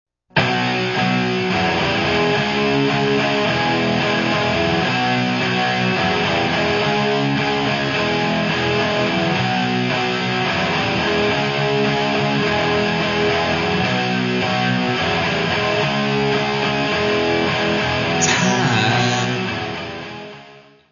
Gravado no Clubhouse, Burbank, Califórnia
Music Category/Genre:  Pop / Rock